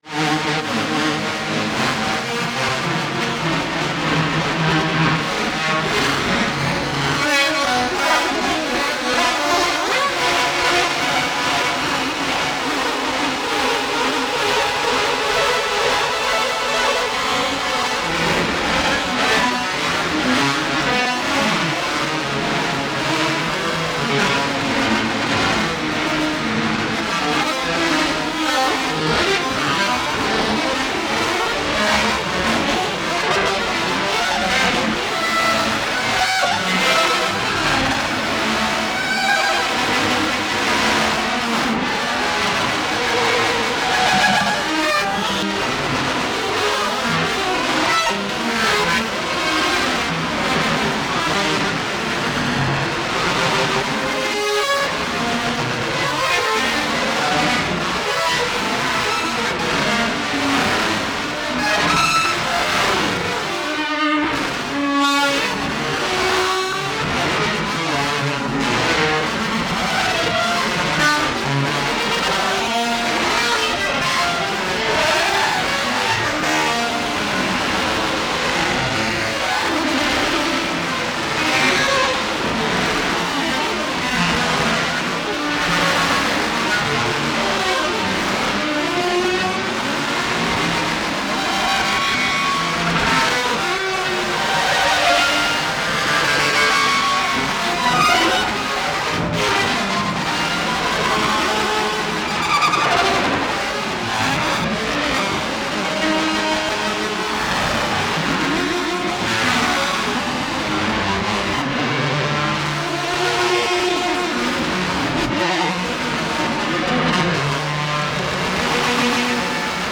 その艶かしい動きは、有機的に変動していく律動。
有機的で奔放な生命力溢れる即興の印象を与えます。
エロティックな機械の軋み、或はメカニカルな生命の息吹、その真逆のものが同一時空に美しいカオスを作っているような。
現代音楽、先端的テクノ、実験音楽をお好きな方々にはもちろん、ギター愛好家の方々にもお薦めのアルバムです。